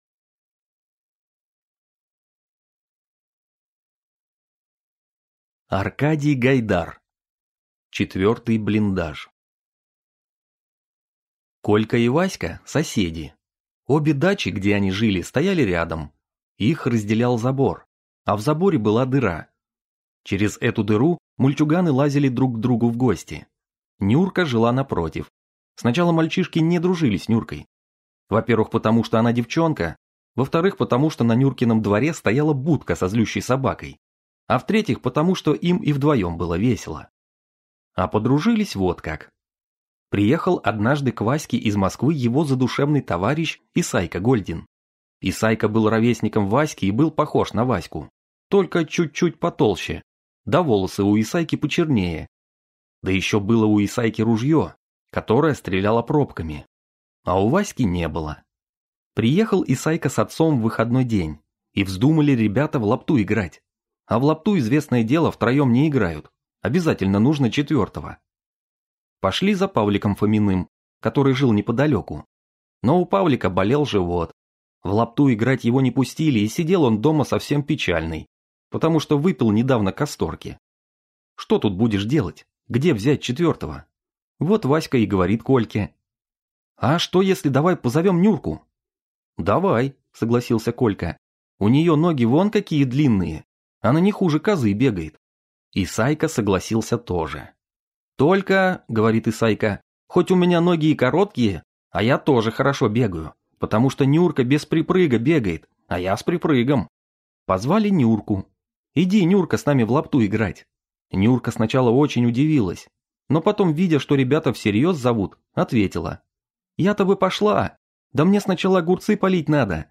Аудиокнига Четвертый блиндаж | Библиотека аудиокниг